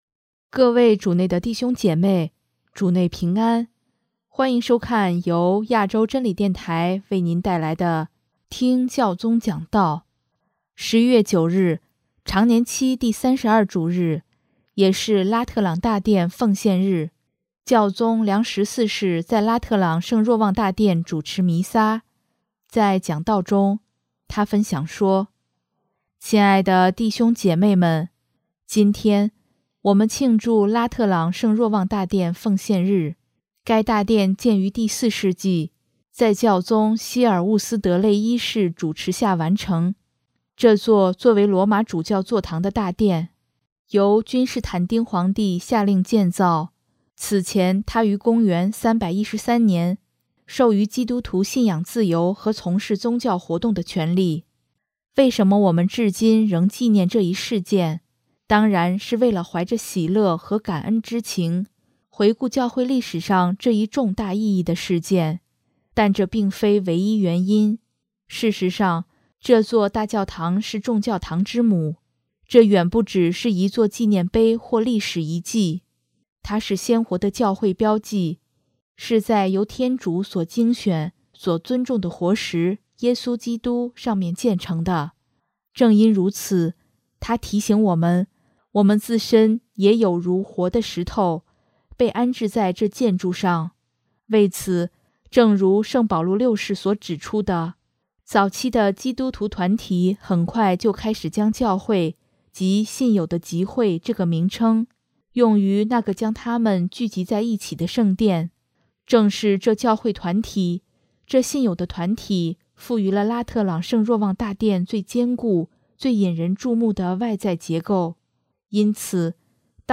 【听教宗讲道】真正信仰团体的建立唯有依靠谦逊和耐心
11月9日，常年期第三十二主日，也是拉特朗大殿奉献日，教宗良十四世在拉特朗圣若望大殿主持弥撒，在讲道中，他分享说：